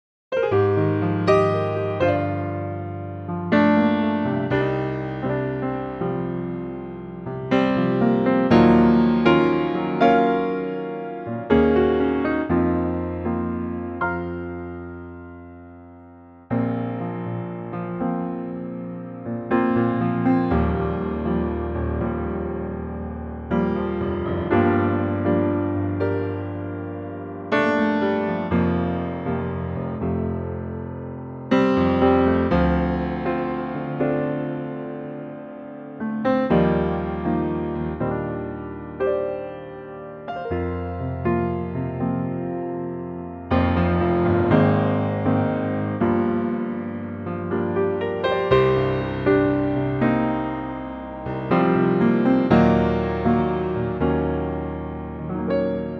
4 bar intro and vocal in at 14 seconds
key change included and stays in tempo all the way.
key - Bb to C - vocal range - F to C (optional F falsetto)
Gorgeous piano only arrangement